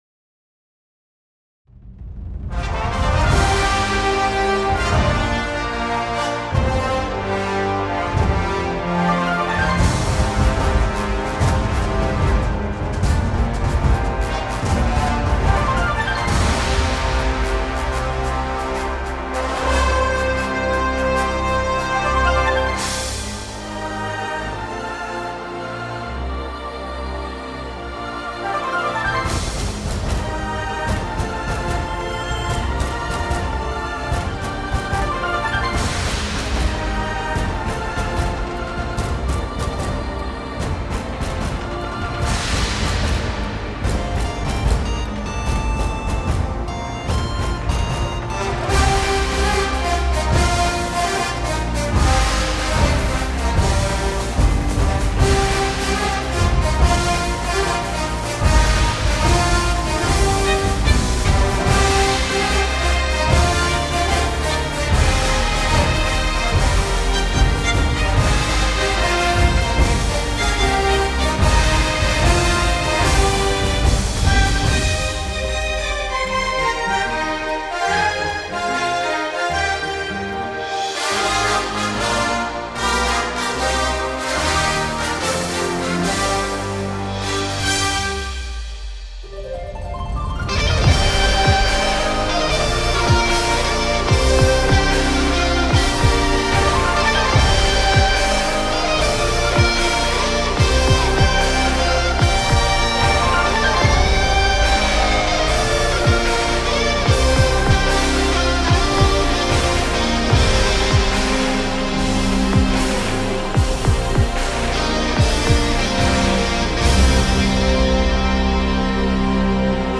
An epic remix
Music / Classical
orchestral cinematic epic